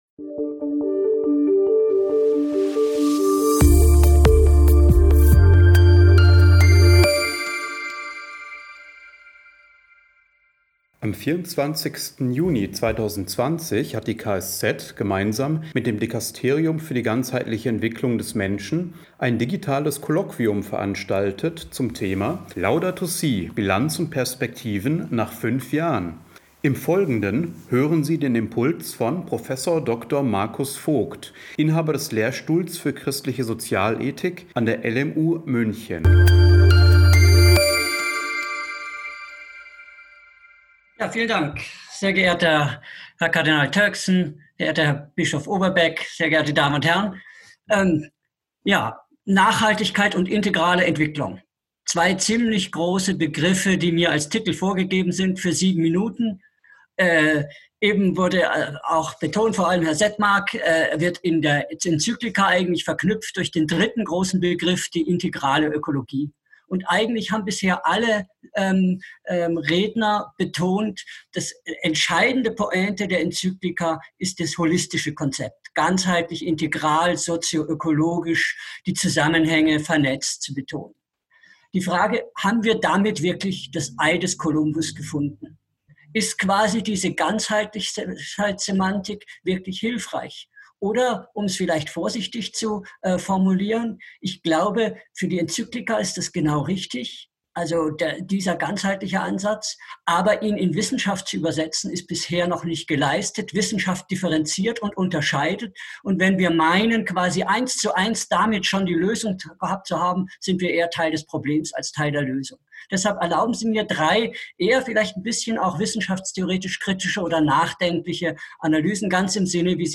Am 24. Juni 2020 fand ein digitales Kolloquium anlässlich des fünften Jubiläums der Enzyklika Laudato si’ statt, das vom Päpstlichen Dikasterium zur Förderung der ganzheitlichen Entwicklung des Menschen und der Katholischen Sozialwissenschaftlichen Zentralstelle (KSZ) organisiert wurde.